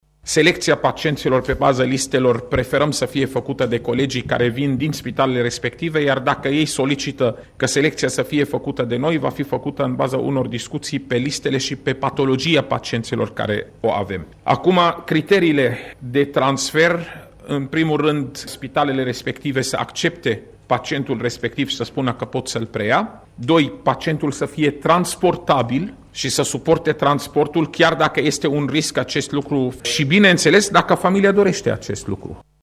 Secretarul de stat, Raed Arafat, a spus că mai multe ţări şi-au exprimat intenţia să preia o parte dintre pacienţi.